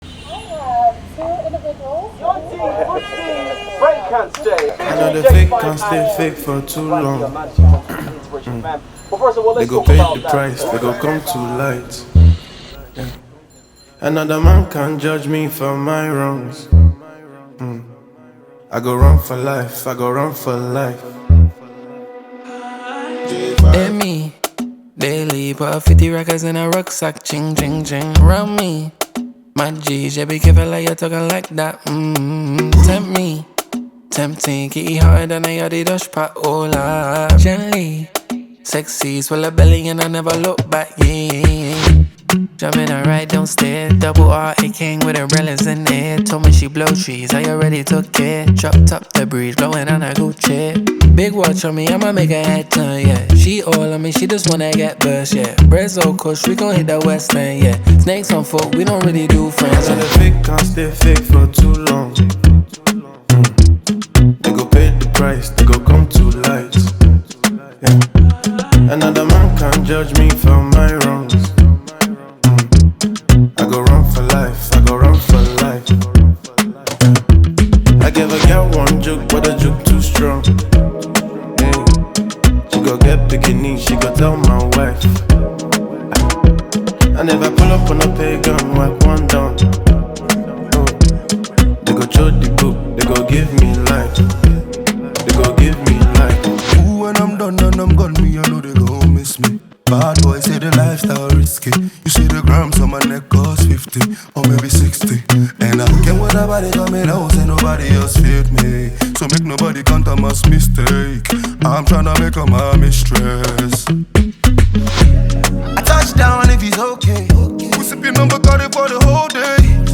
Afro Fuji Pop
Yoruba Fuji song